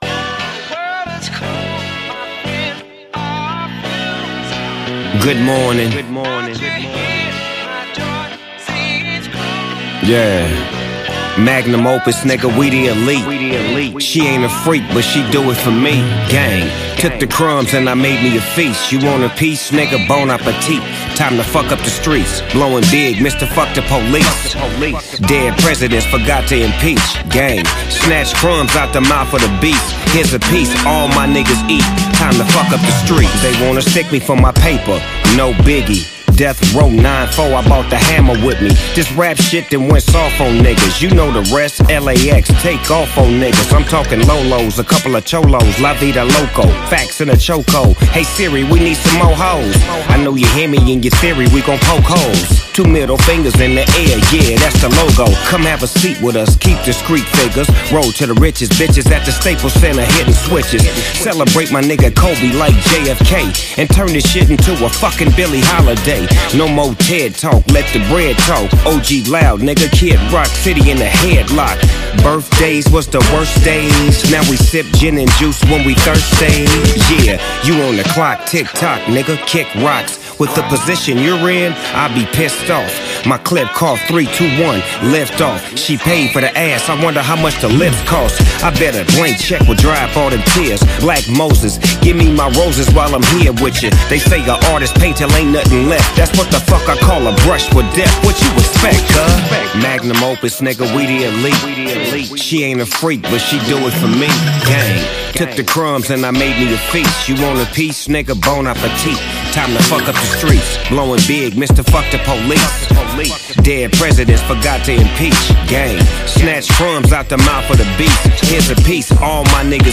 classic West Coast sound with smooth beats and cool lyrics
deep voice and relaxed flow
Fans of old-school hip-hop will love this album.